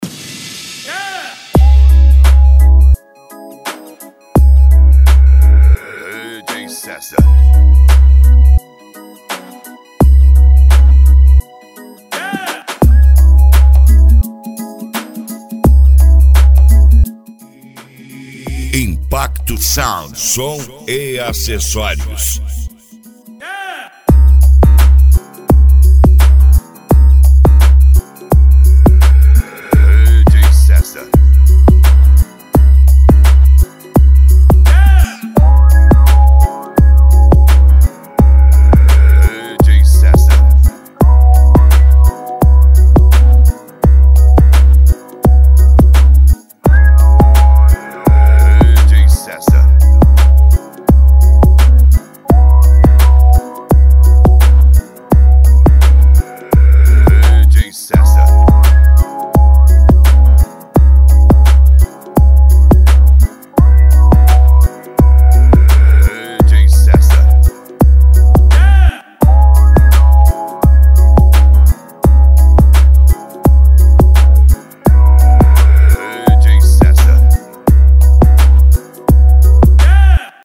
Funk Nejo
Mega Funk